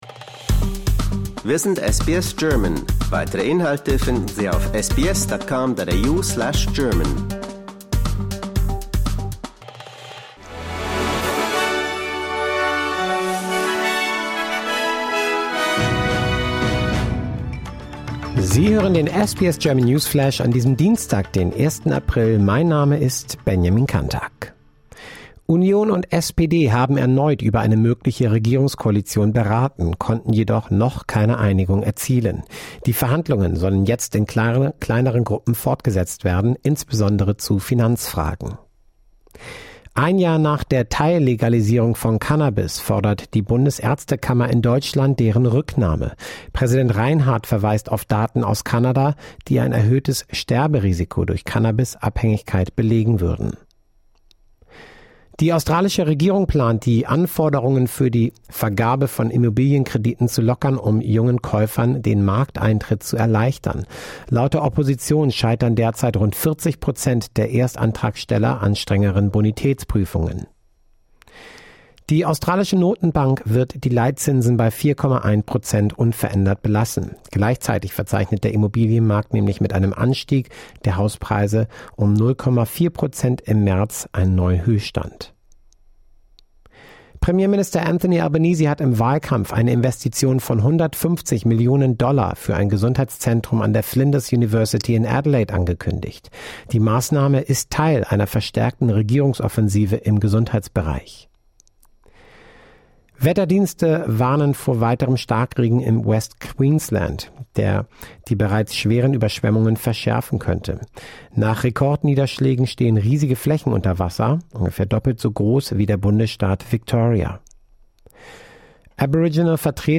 Die wichtigsten Geschehnisse in Australien, Deutschland und der Welt im News-Podcast von SBS German.
Nachrichten